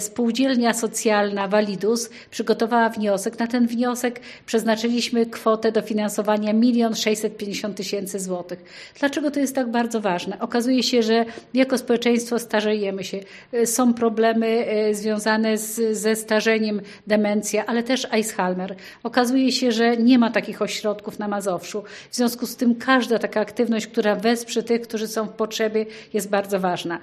O inwestycji mówi członkini zarządu województwa mazowieckiego, Elżbieta Lanc: